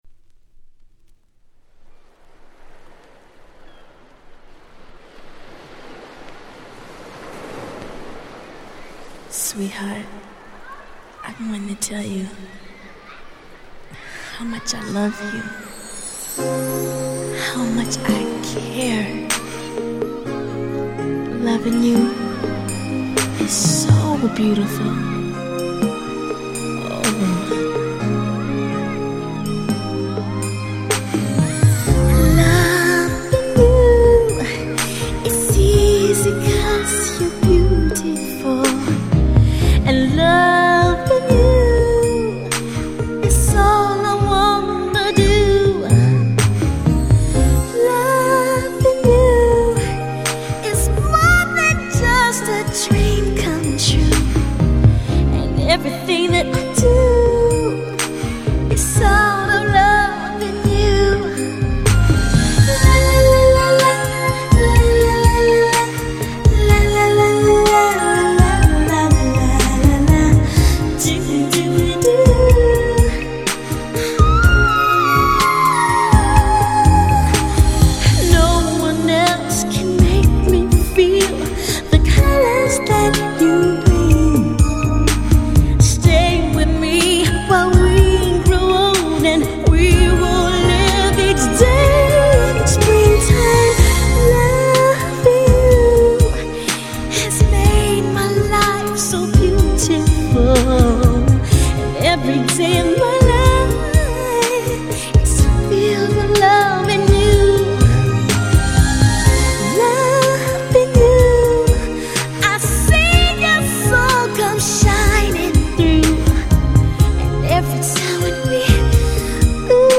【Media】Vinyl 12'' Single (Promo)
彼女のハイトーン・ヴォイスが輝く最高のカバーです！！
スロウジャム バラード